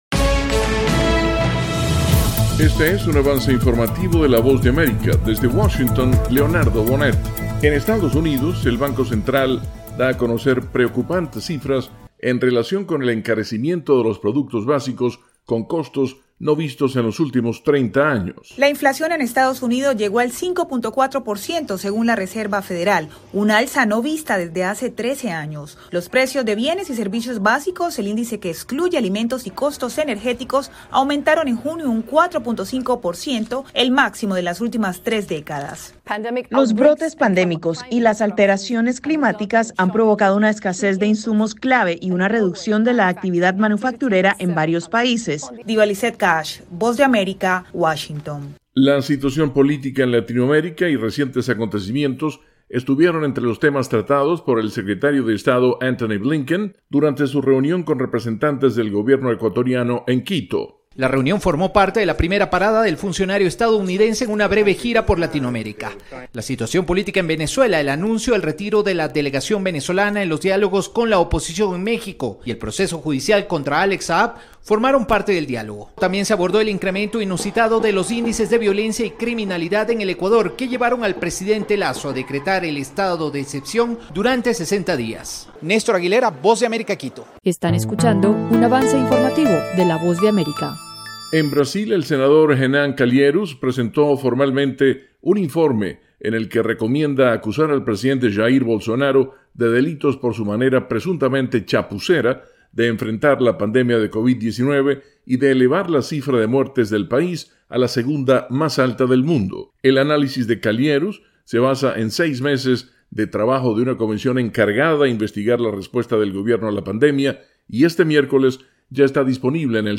Resumen informativo con algunas de las noticias más importantes de Estados Unidos y el resto del mundo.